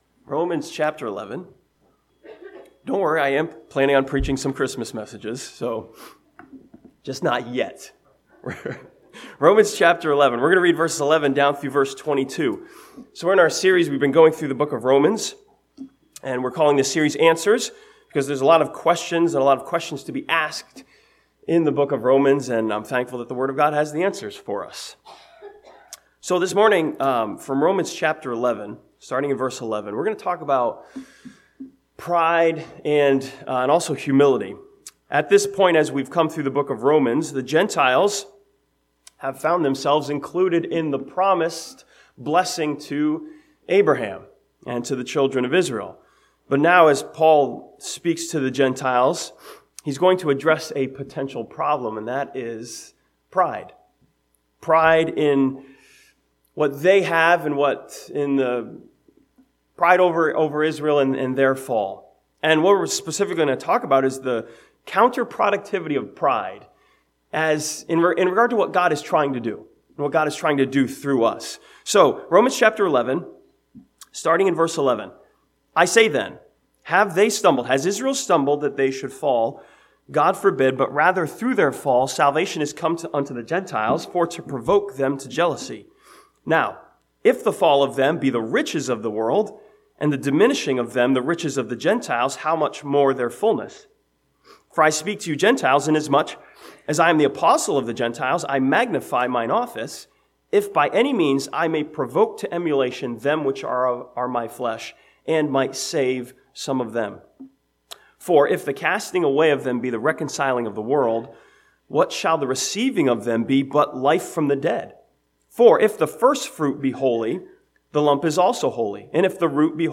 This sermon from Romans chapter 11 asks a question of humility, "Why would I boast?" and finds the answer in our passage.